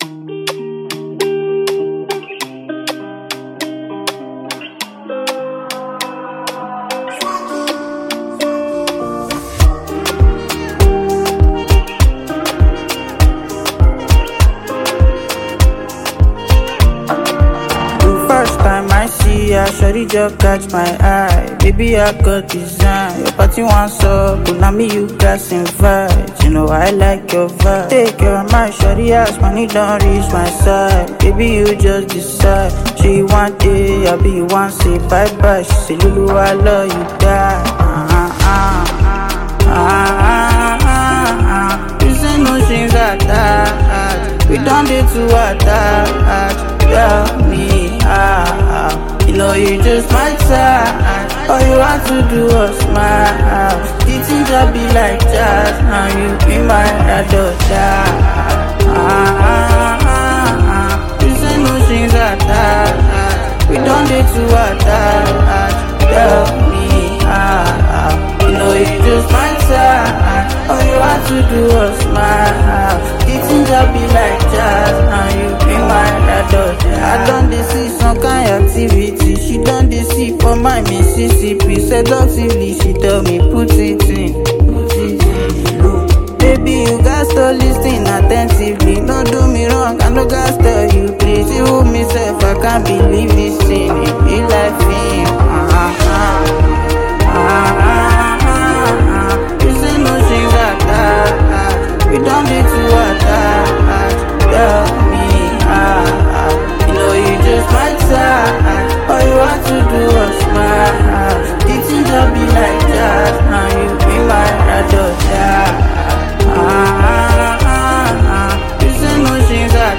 Nigerian talented singer